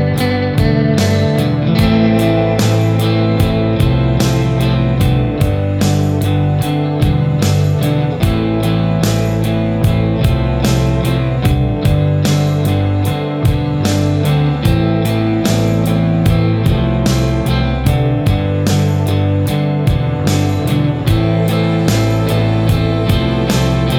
No Lead Guitar Pop (2000s) 5:57 Buy £1.50